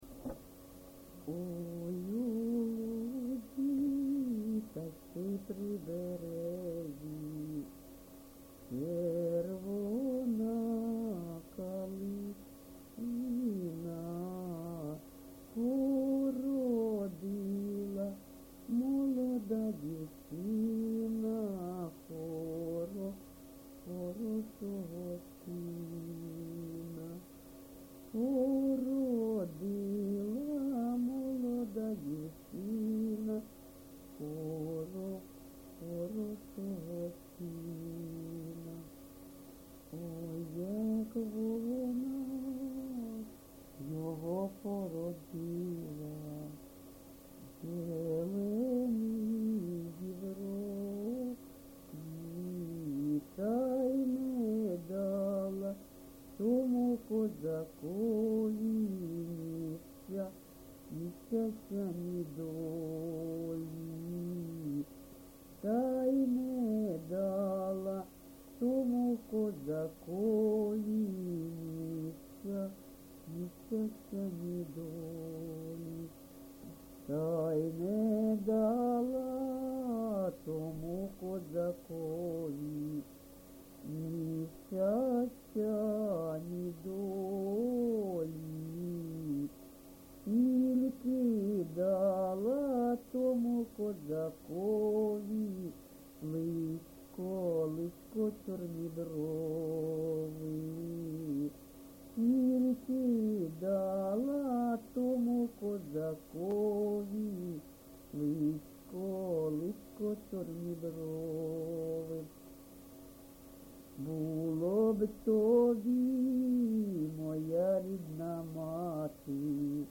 ЖанрПісні з особистого та родинного життя
Місце записум. Бахмут, Бахмутський район, Донецька обл., Україна, Слобожанщина